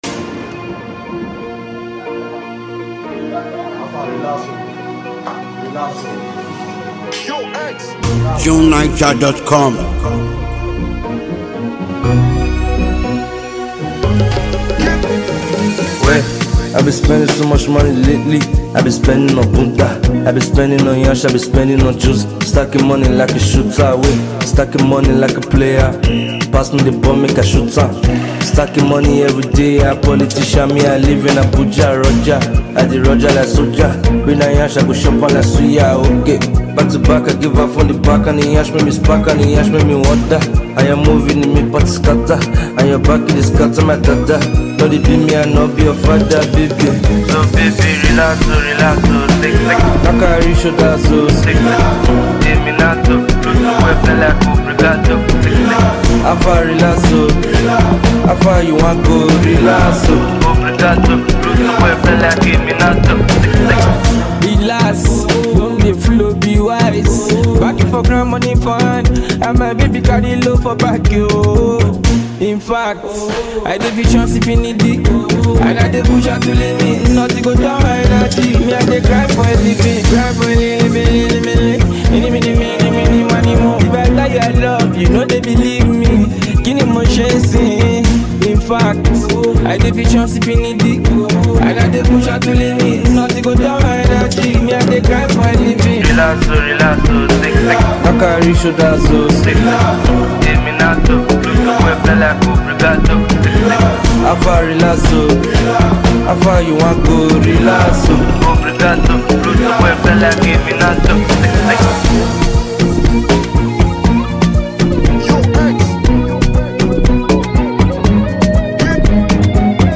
For anyone who like upbeat, imaginative music
is a carefree groove that will keep them moving all day.